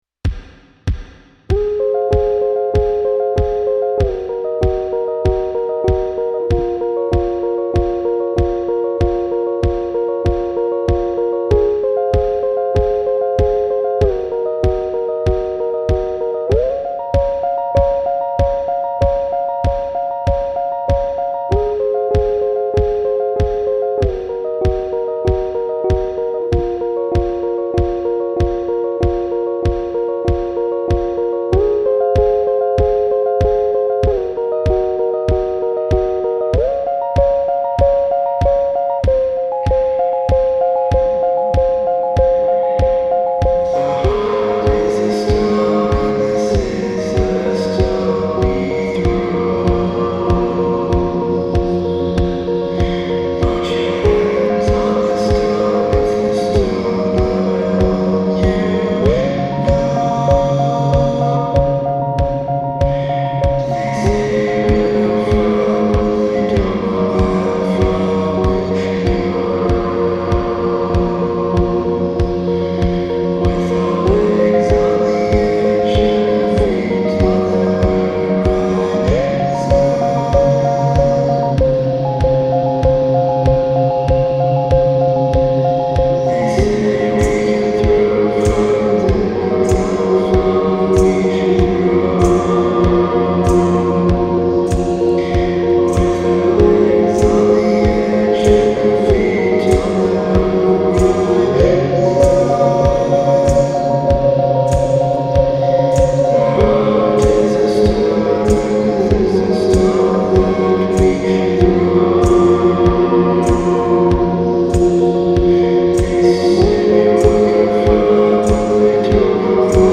experimental band
distorted baritone
one even further out in spaceland than the original.